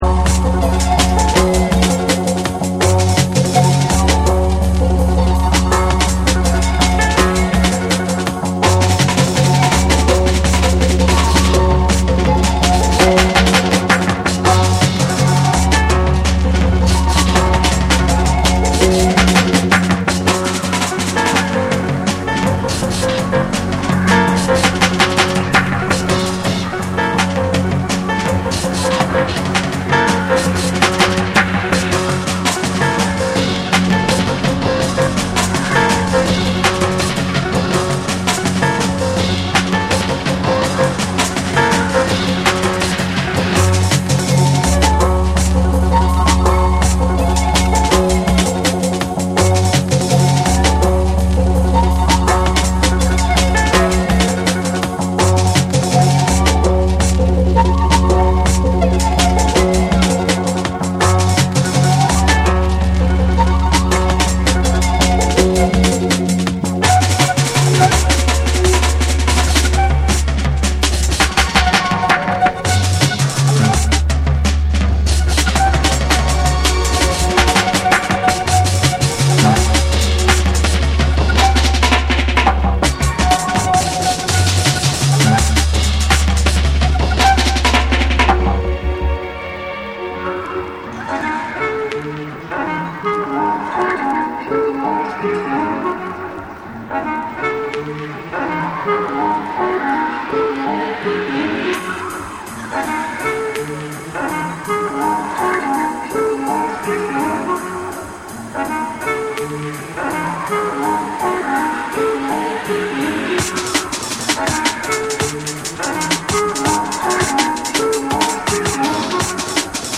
重厚なビートと細密なサウンド・デザインが炸裂。
BREAKBEATS